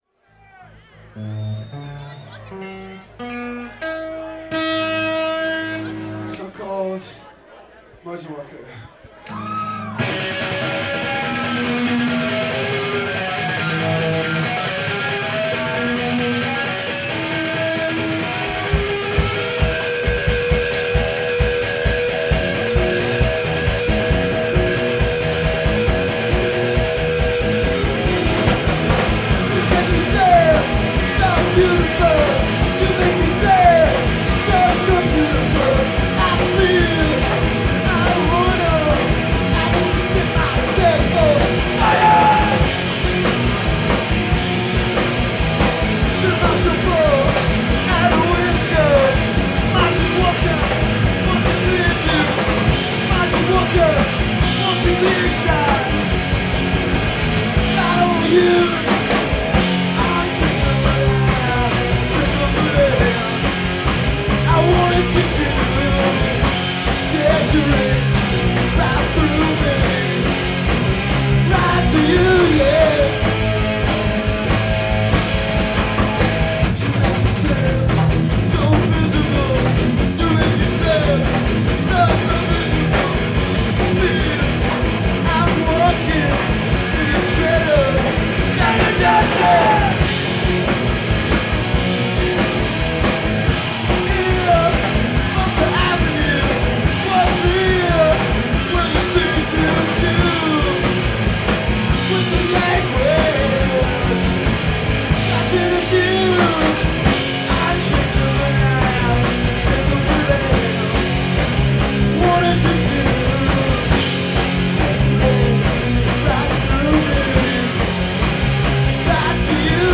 Live at Border
in Burlington, Vermont